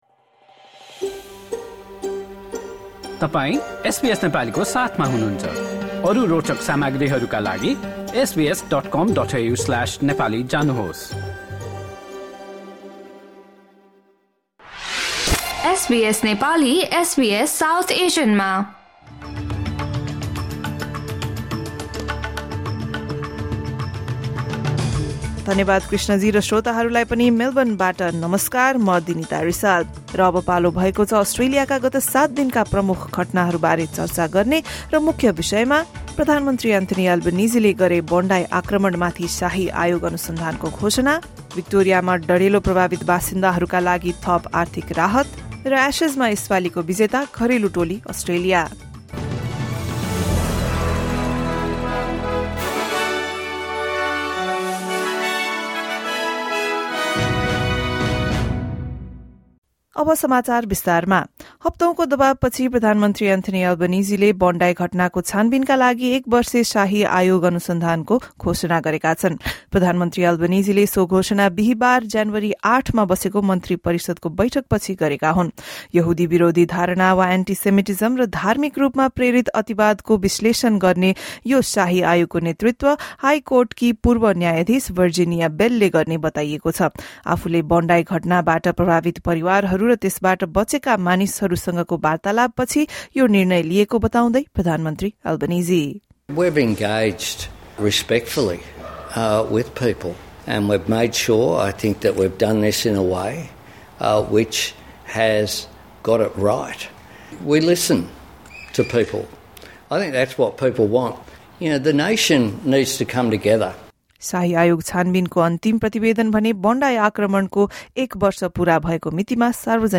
प्रधानमन्त्री एन्थोनी आल्बानिजीद्वारा बोन्डाइ घटनामा शाही आयोगको घोषणा, भिक्टोरियामा डढेलो प्रभावितहरूका लागि थप आर्थिक राहत र एसेज शृङ्खलामा घरेलु टोली अस्ट्रेलियाको जित लगायत एक हप्ता यताका प्रमुख घटनाहरू बारे एसबीएस नेपालीबाट समाचार सुन्नुहोस्।